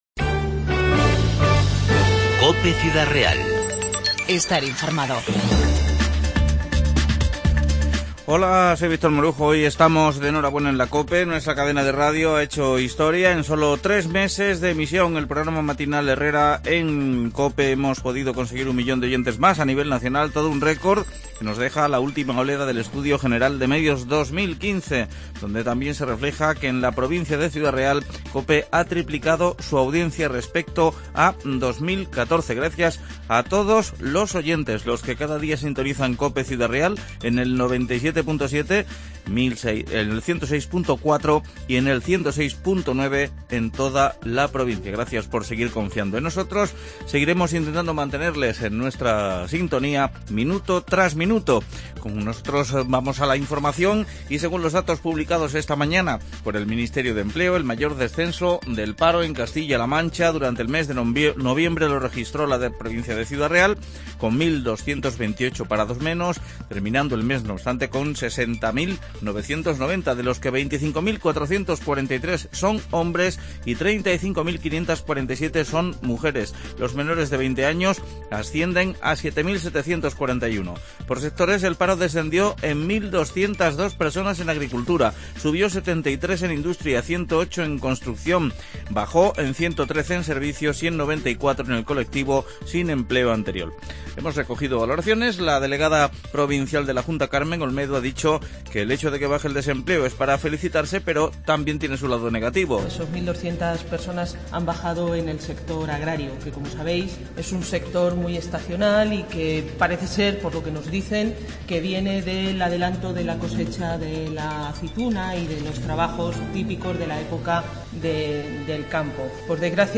INFORMATIVO 2-12-15